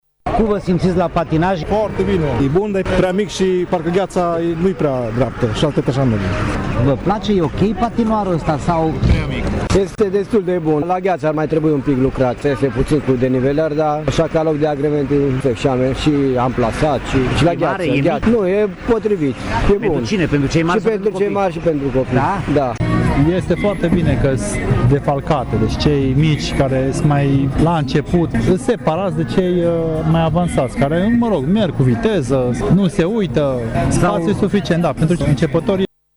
Tg.mureșenii se bucură de acest patinoar, însă cred că suprafața e prea mică, mai ales pentru cei avansați. Unii patinatori se plâng și de calitatea gheții:
patinatori.mp3